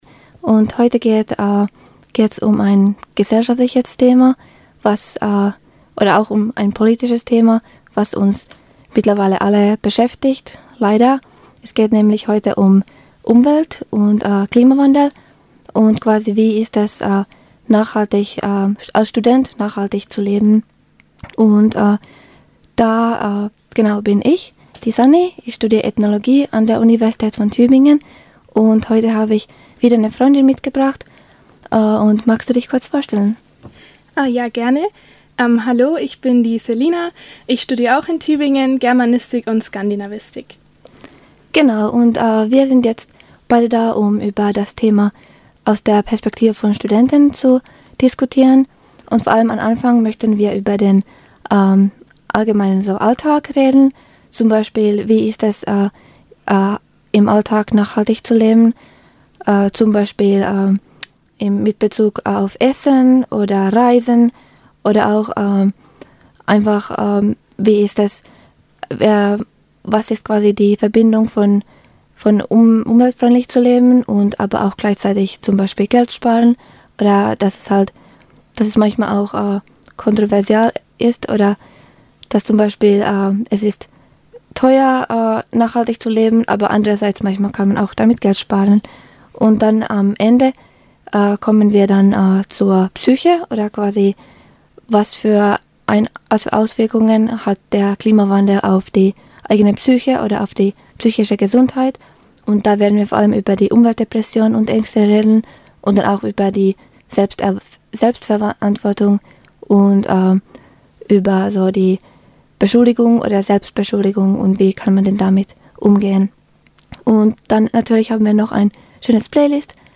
Freies Radio Wüste Welle - Eine Stimme aus der Menge - Eine Stimme aus der Menge: Kann ein Student das Klima retten?